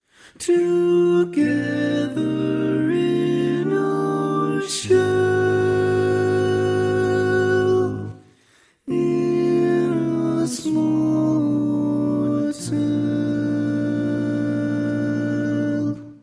Key written in: B♭ Major
Type: Barbershop